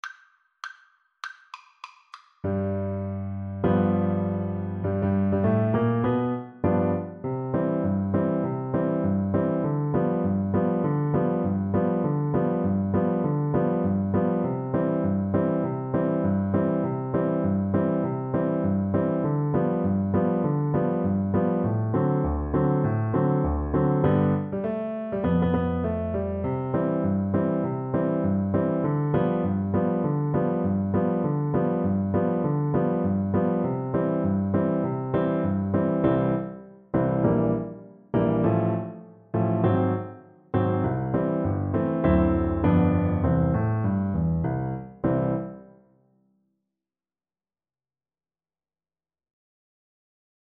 Fast swing =c.200
Jazz (View more Jazz Piano Duet Music)